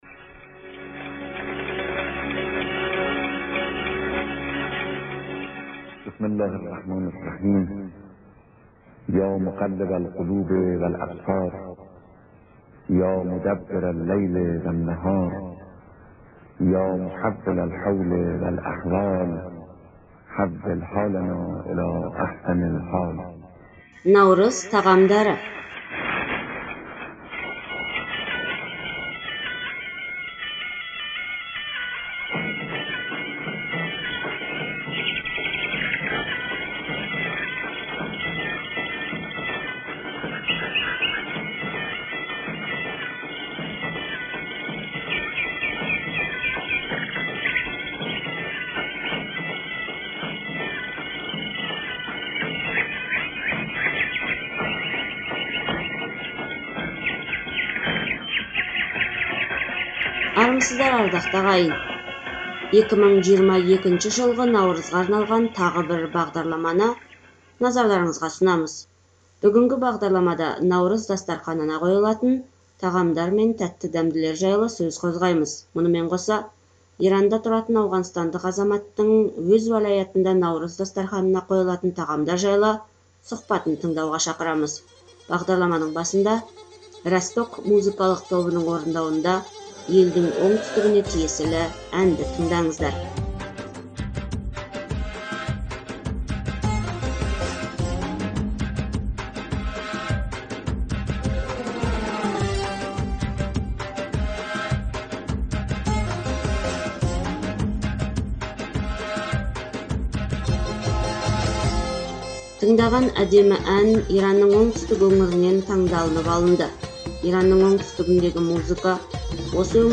Мұнымен қоса, Иранда тұратын ауғанстандық азаматтың өз уәлятында Наурыз дастарханына қойылатын тағамдар жайлы сұхбатын тыңдауға шақырамыз.
Иранның оңтүстігіндегі музыка осы өңірдің ыстық табиғатынан шабыт алғандықтан шаттық пен энергияға толы.